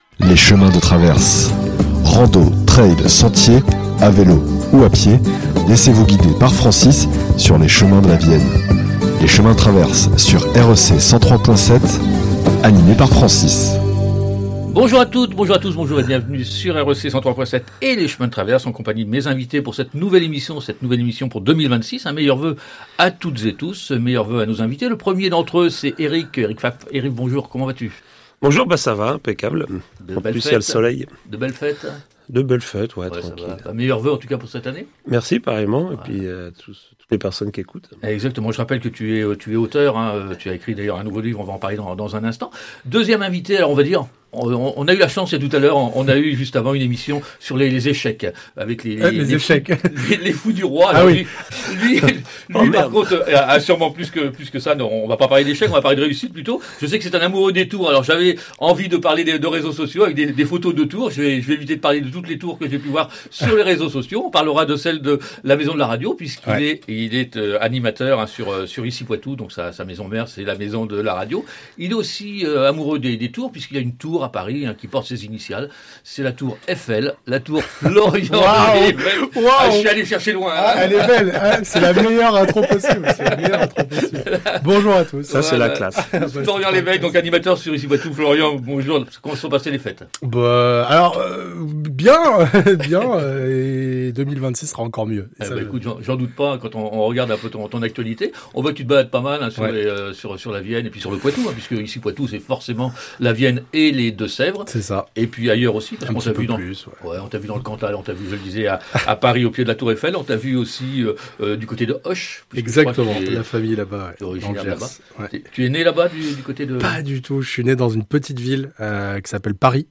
recevait deux passionnés.